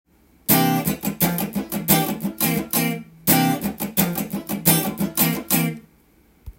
パターン①はC7(9)にカッティングを混ぜて
C7にしたりとテンションコードとカッティング奏法の
合体したギターパートになっています。